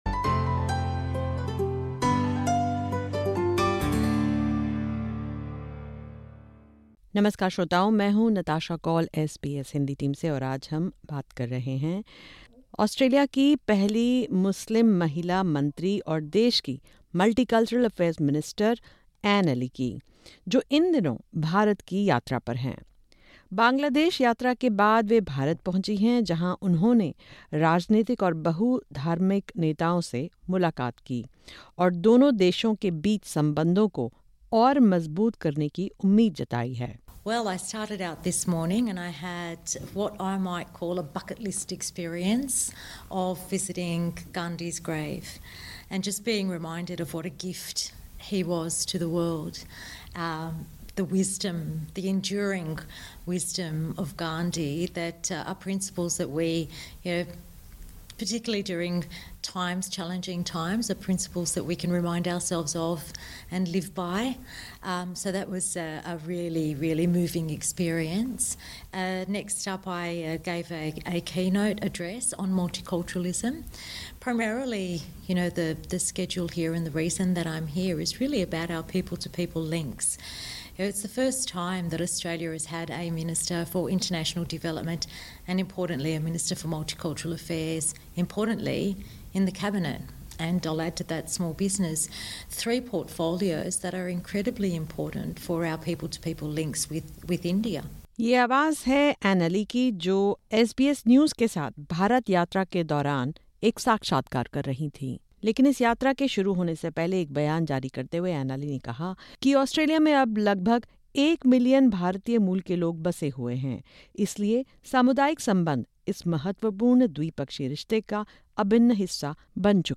Australian Minister Dr Anne Aly, who holds portfolios for International Development, Small Business and Multicultural Affairs, is visiting India to strengthen people-to-people connections. Highlighting that nearly one million Australians have Indian ancestry, she emphasised in an exclusive interview with SBS News the importance of community ties in Australia–India relations. Her visit to India comes after a trip to Bangladesh, with both visits focused on strengthening Australia’s engagement in the Indian Ocean region.